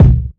Boom-Bap Kick 78.wav